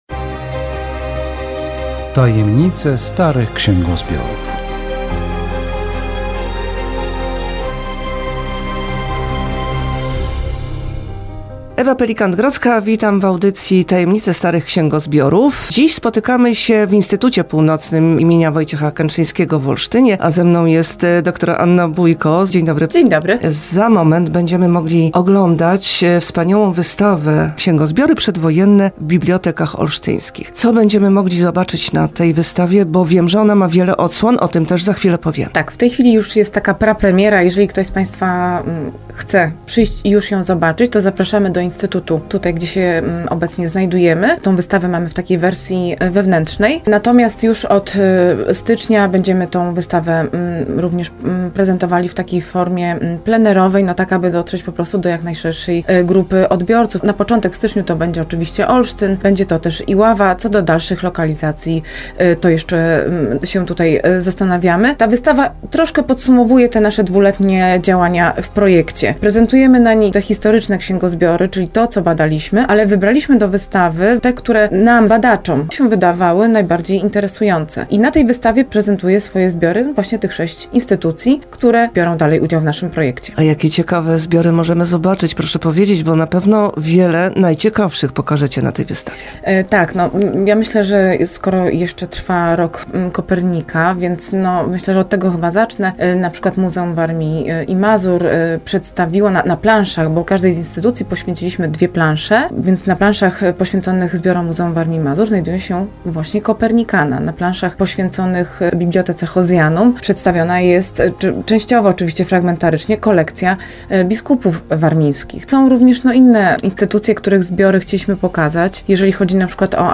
Audycja radiowa "Tajemnice starych księgozbiorów" zawierająca zapowiedź wernisażu wystawy "Księgozbiory przedwojenne w bibliotekach olsztyńskich".